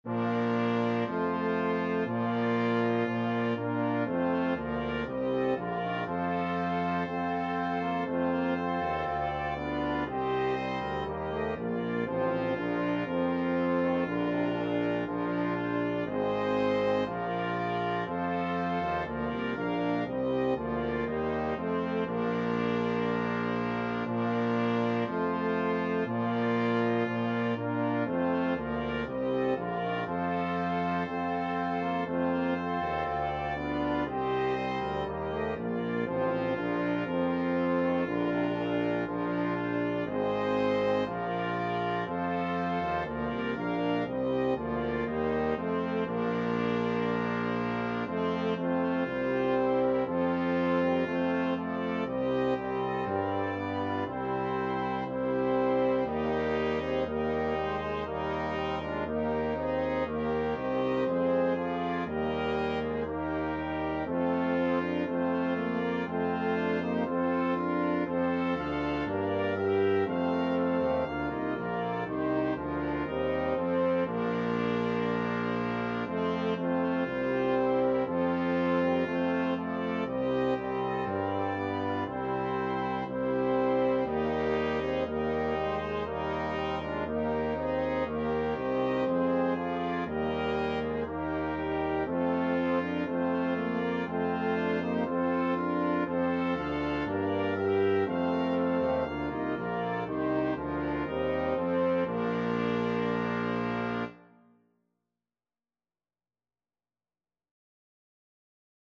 Free Sheet music for Brass Quintet
Trumpet 1Trumpet 2French HornTromboneTuba
Bb major (Sounding Pitch) (View more Bb major Music for Brass Quintet )
2/2 (View more 2/2 Music)
Brass Quintet  (View more Intermediate Brass Quintet Music)
Classical (View more Classical Brass Quintet Music)